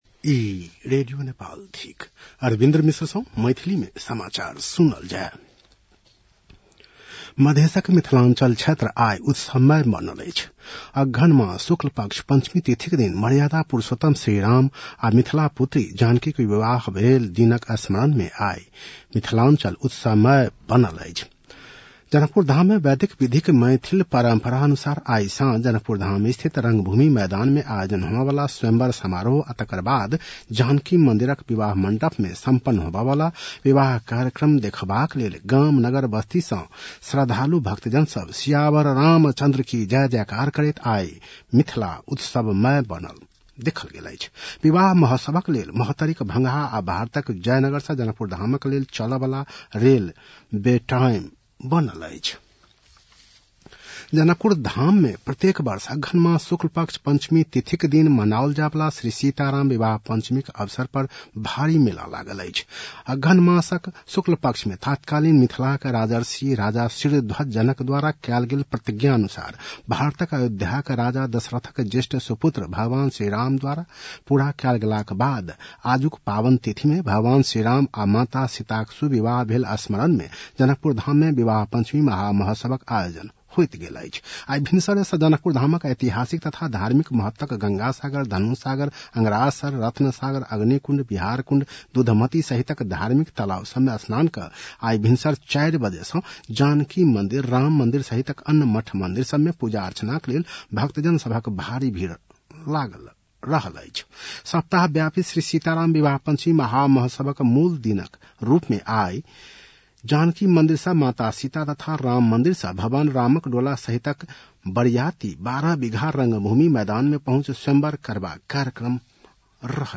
मैथिली भाषामा समाचार : २२ मंसिर , २०८१